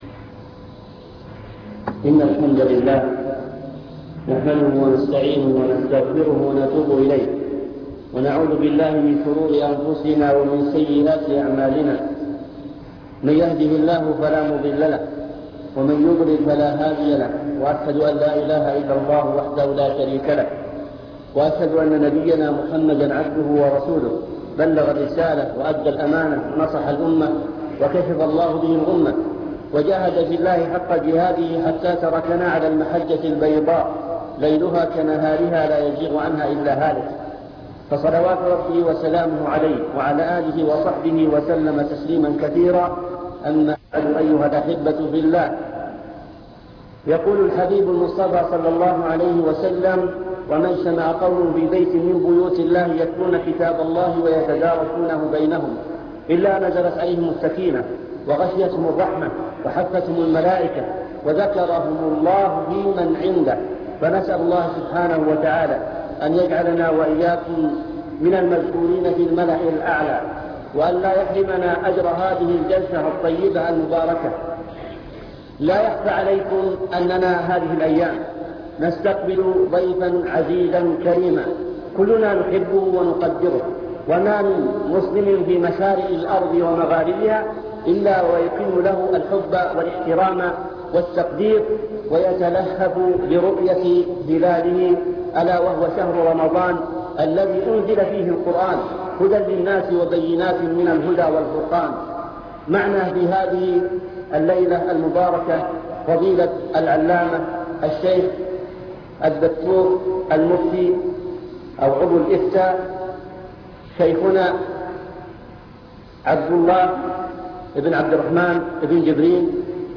المكتبة الصوتية  تسجيلات - محاضرات ودروس  مجموعة محاضرات ودروس عن رمضان كيفية استقبال شهر رمضان